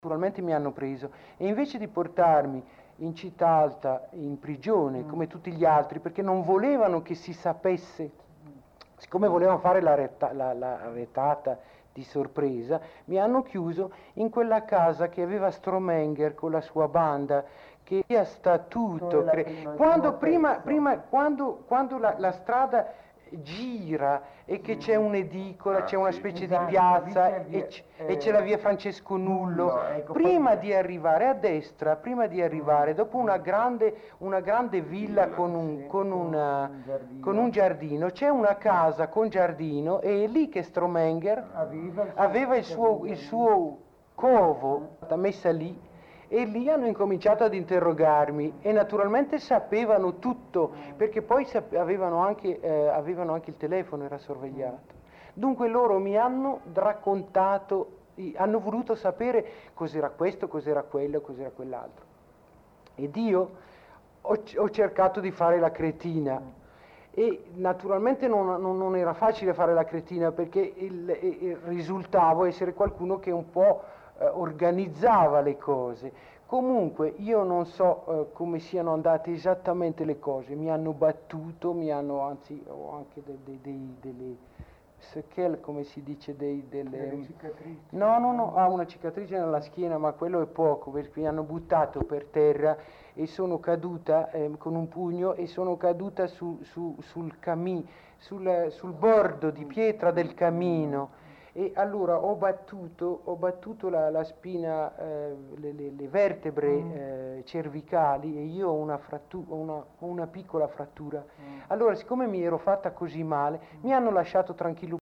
intervistata a Bergamo il 24 luglio 1978